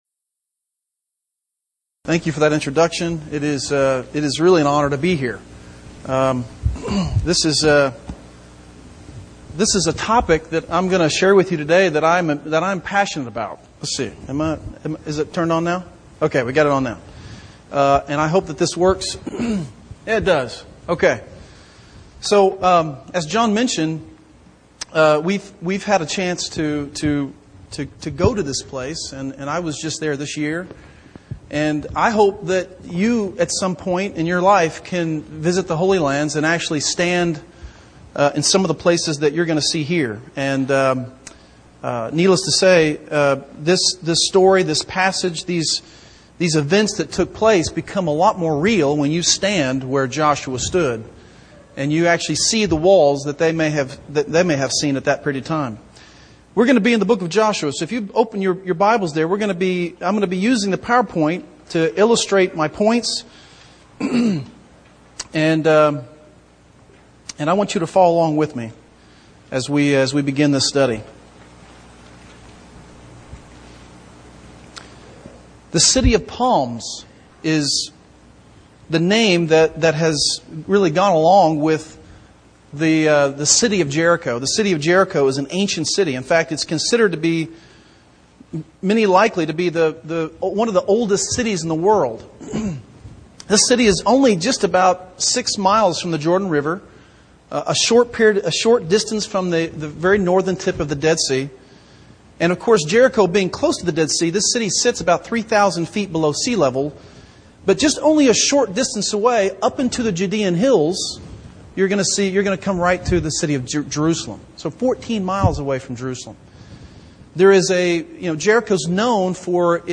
Event: 2015 Discipleship University
lecture